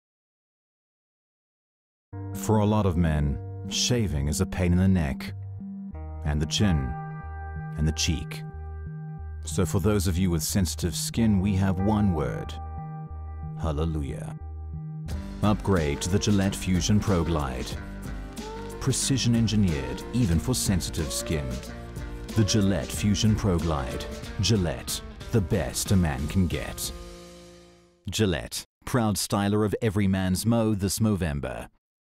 South African English Speaker with Neutral Accent.
Sprechprobe: Werbung (Muttersprache):